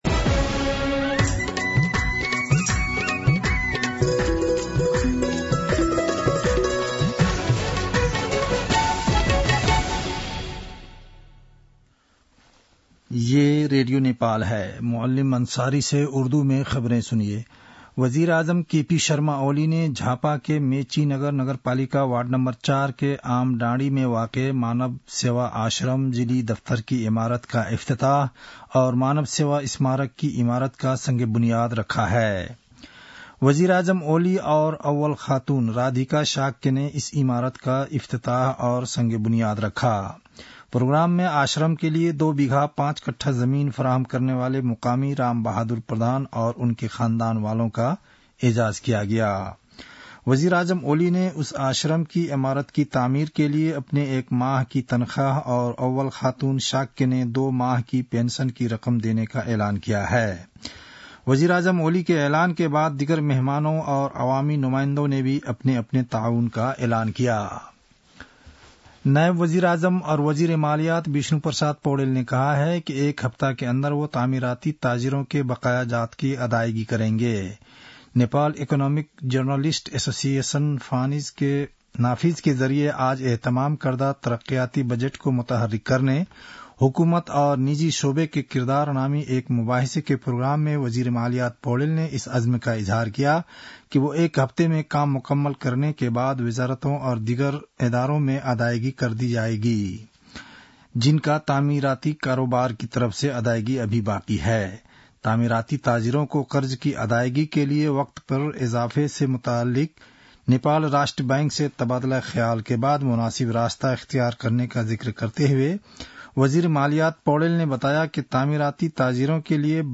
उर्दु भाषामा समाचार : २४ मंसिर , २०८१
Urdu-News-8-23.mp3